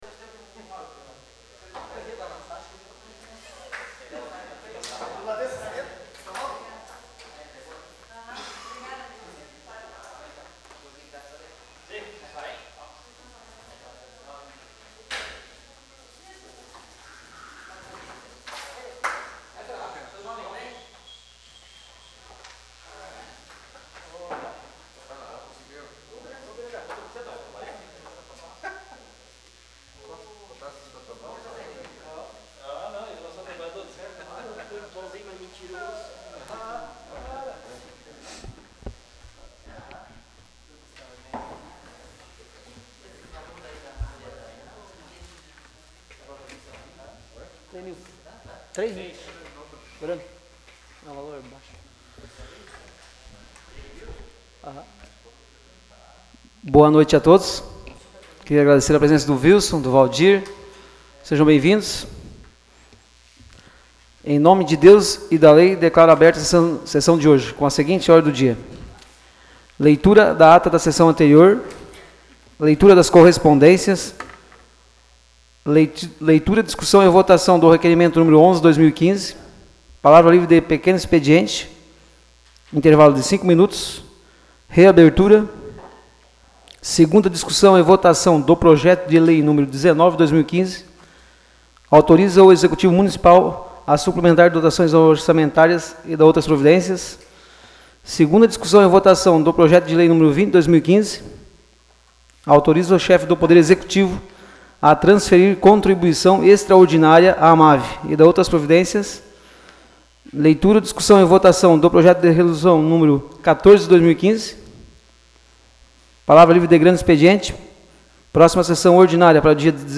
Áudio da Sessão Ordinária de 09 de novembro de 2015.